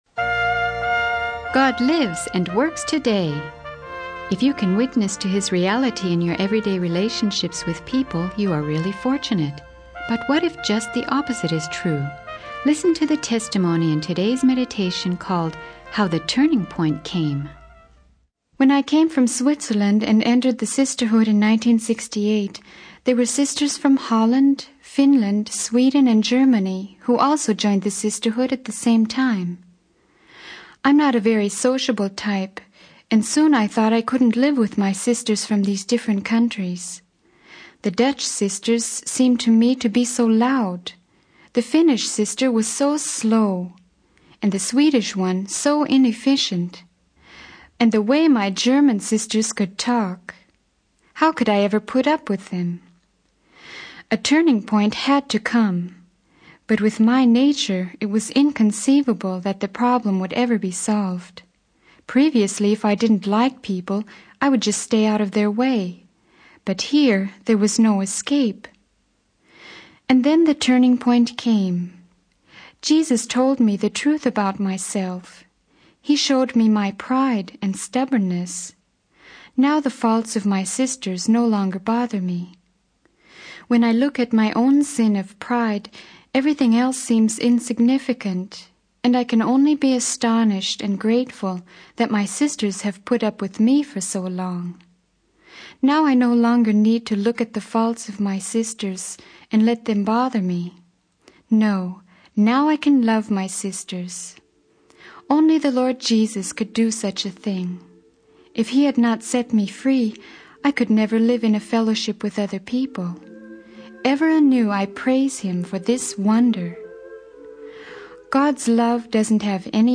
In this sermon, the speaker emphasizes the importance of love and not erecting barriers between oneself and others.